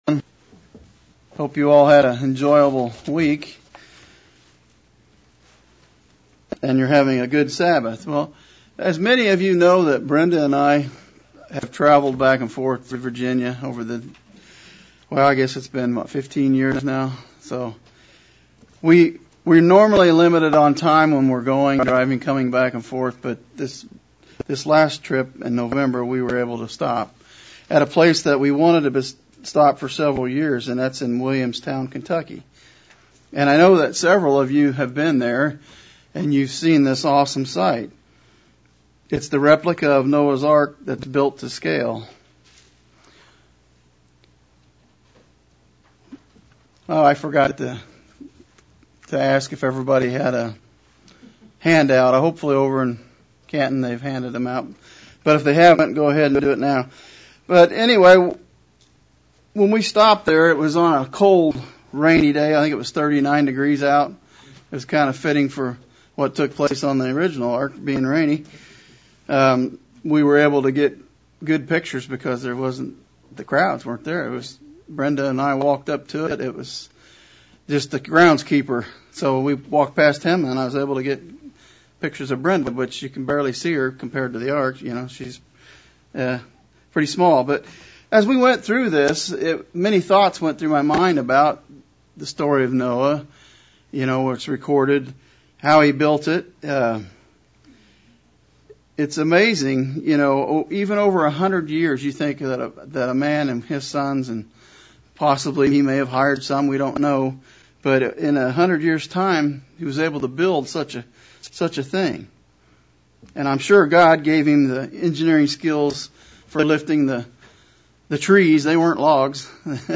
Sermon looking at the conditions of society in the days of Noah and comparing them with the conditions that we live in today. Are we ready and prepared for the end times?